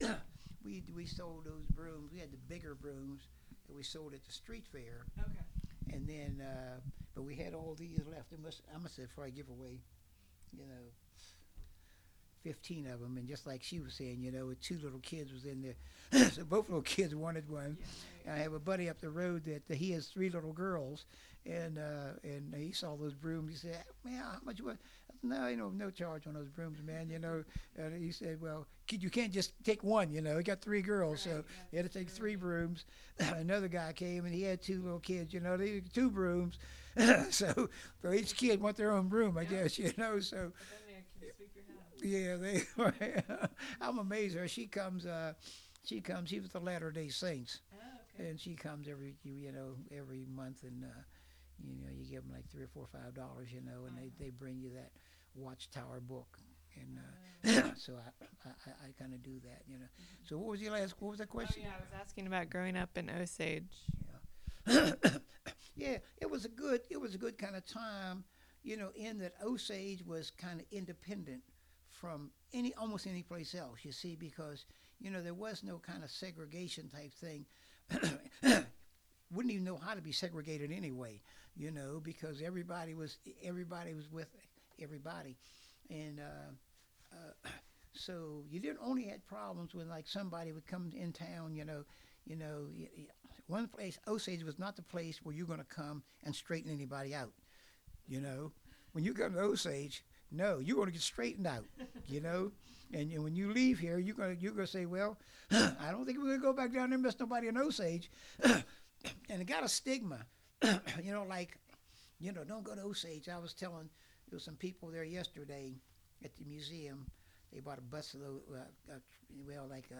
Oral history
This interview is part of a collection of interviews conducted with Scotts Run natives/residents and/or members of the Scotts Run Museum.